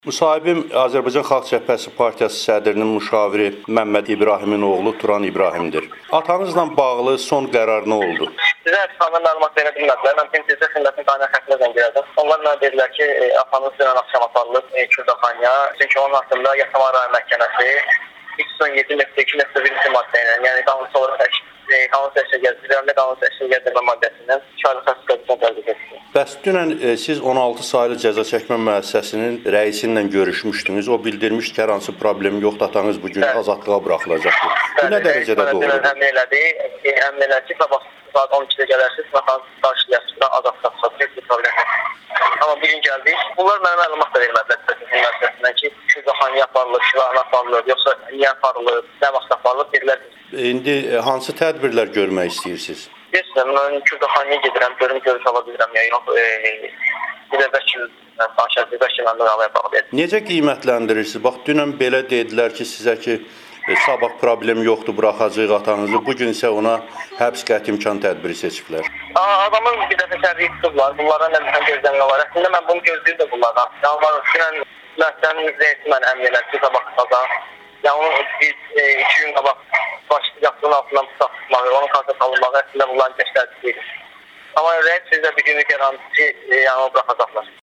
müsahibəsi